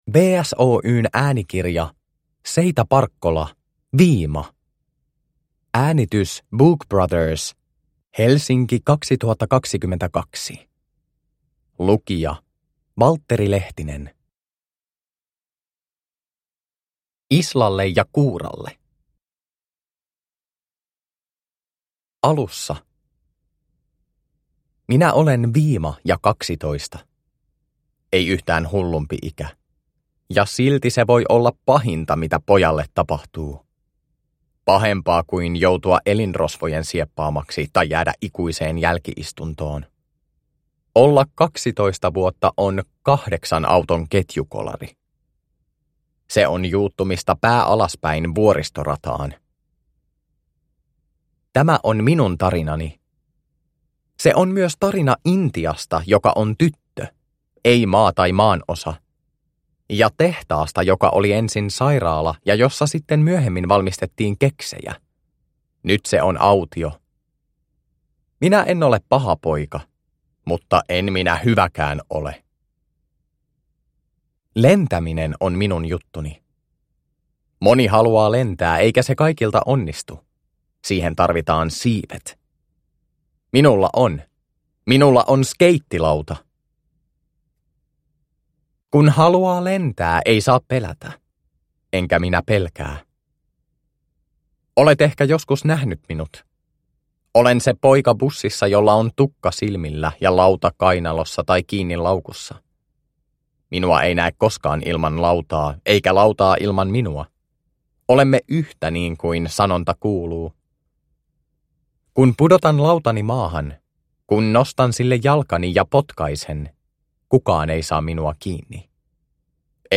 Viima – Ljudbok – Laddas ner